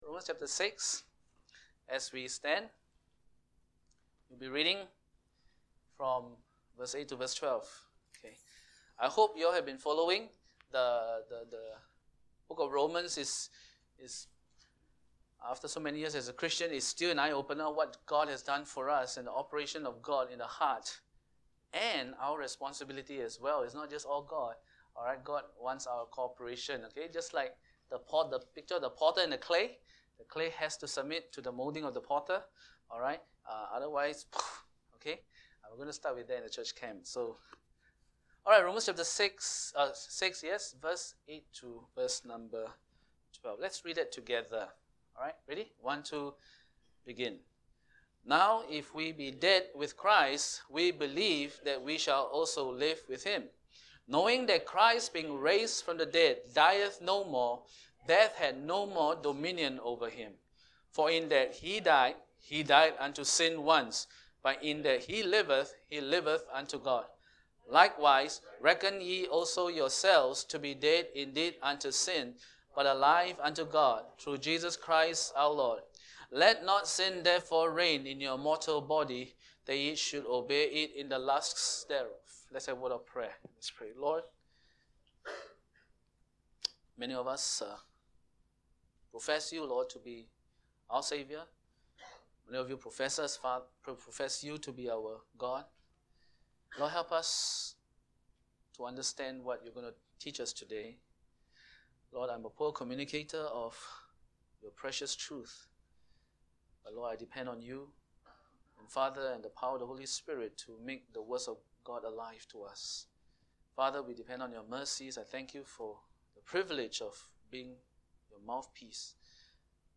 Sunday Worship Service 100618 Jesus gives to us eternal life, but He also gives unto us the power to live for Him.